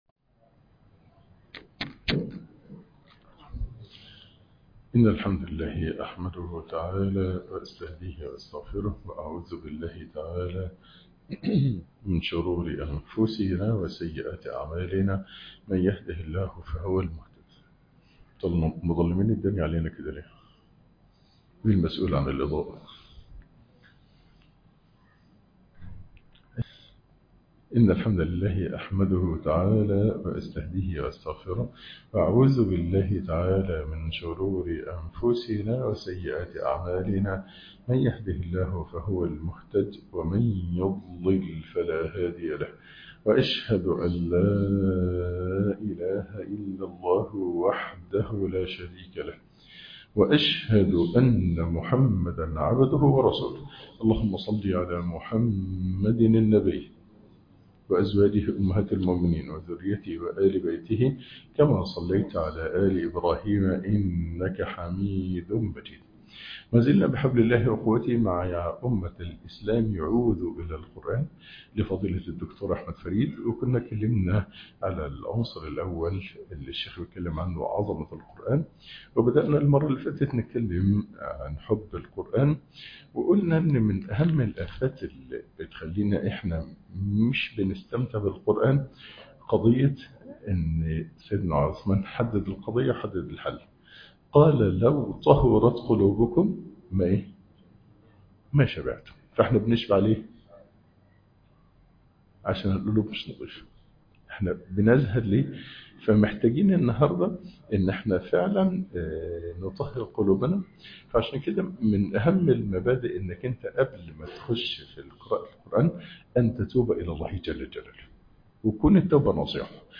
ياأمة الإسلام عودوا إلى القرأن - الدرس السادس